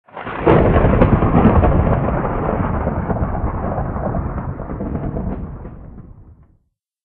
Оригинальные игровые эффекты помогут добавить атмосферу Майнкрафта в ваши видео.
Звук грозы из игры Minecraft